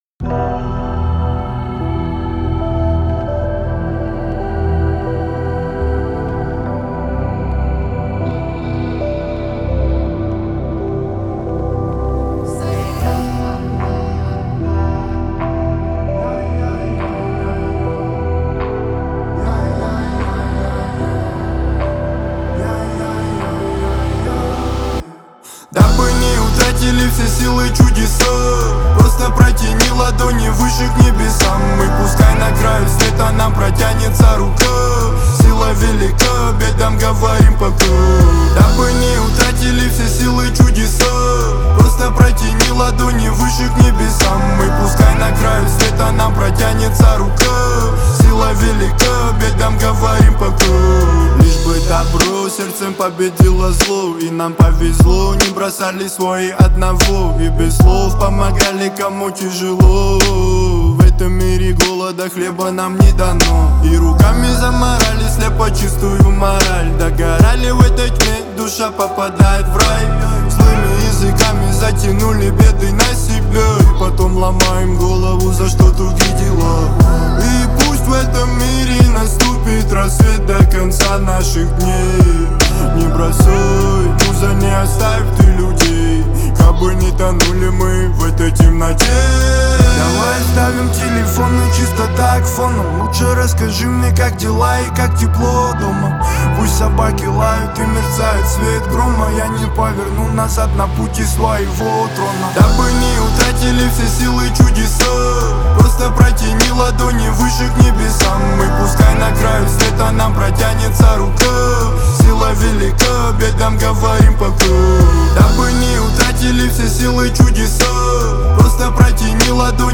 свежие электронные аранжировки, создавая атмосферу праздника